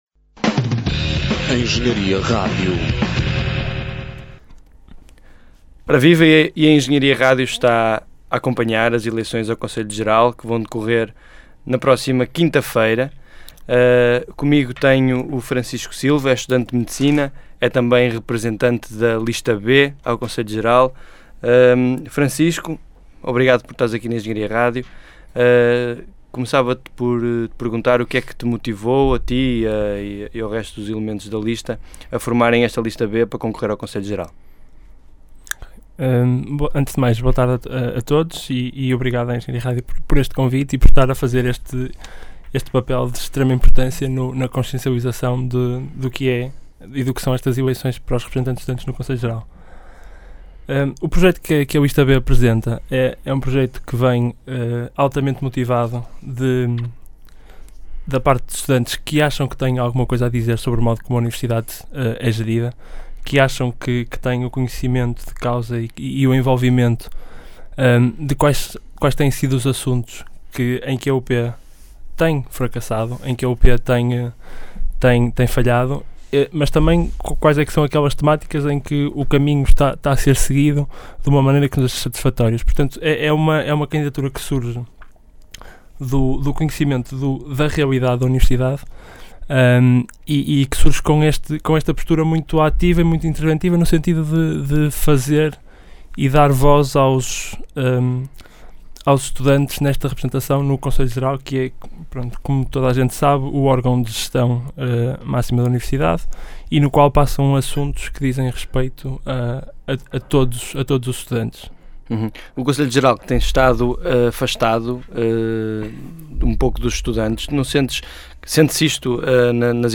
A engenharia rádio promoveu durante o dia de hoje uma série de entrevistas às listas candidatas à representação dos estudantes do Conselho Geral da Universidade do Porto.